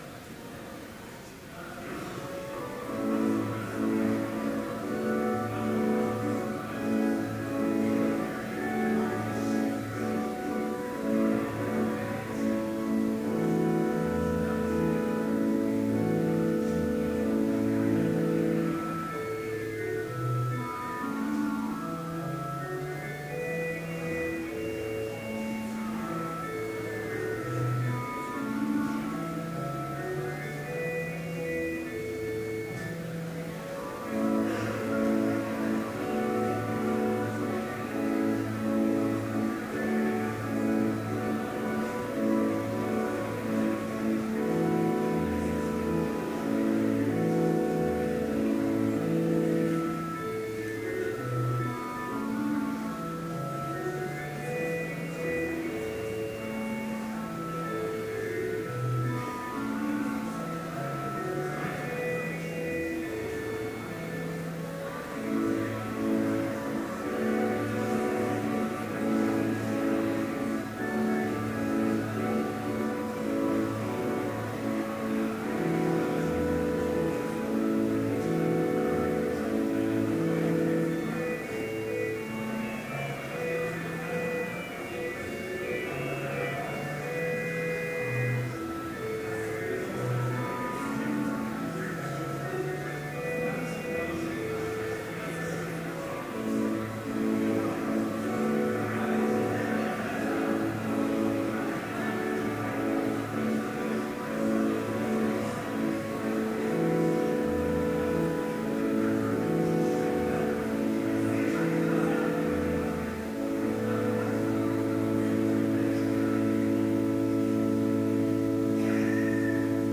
Complete service audio for Chapel - March 27, 2013